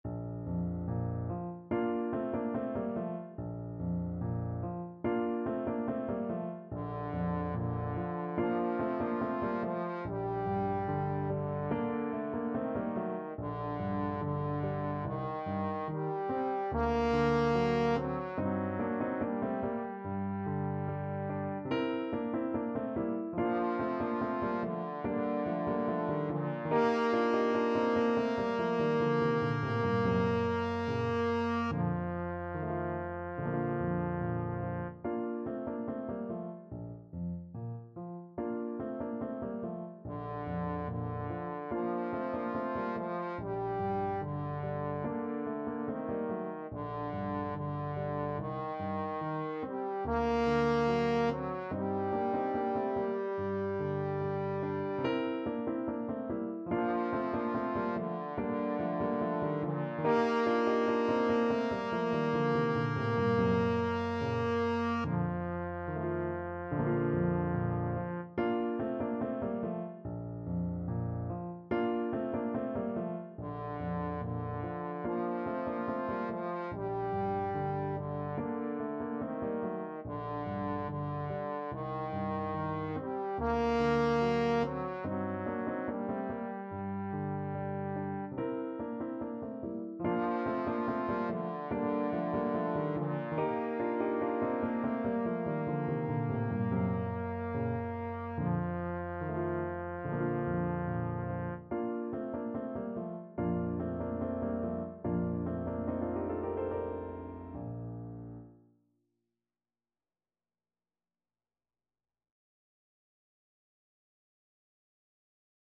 Trombone Classical
Tempo Marking: Allegretto
Bb major